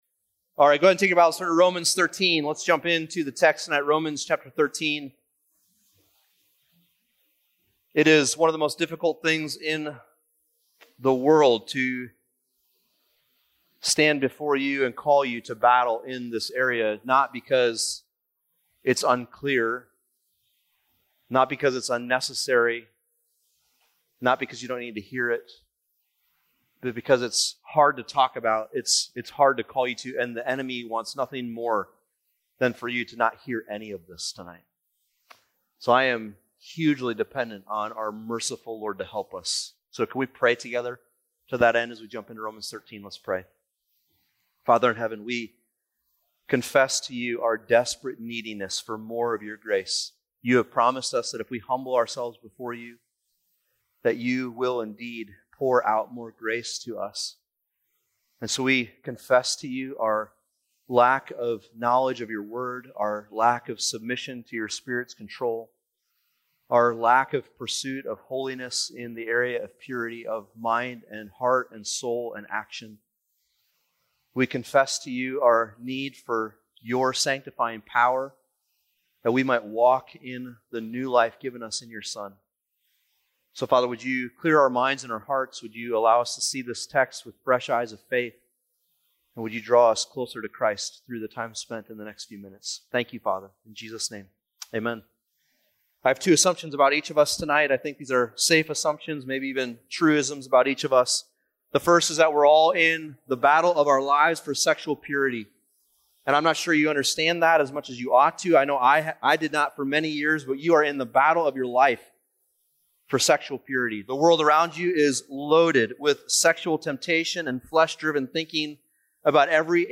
College/Roots Roots Summer Retreat - 2021 Audio ◀ Prev Series List Next ▶ Previous 1.